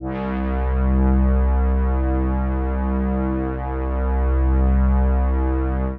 C2_trance_pad_1.wav